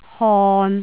韻母 im, in 到頁頂
還記得第二課的韻母嗎？把它們加上鼻音，便是本課的韻母了。
如果你把上列字例拉長來讀，會聽到韻母〔i〕夾在中間。其實，這些字的韻母都是〔i〕再加上一個鼻音，分別有〔im〕和〔in〕。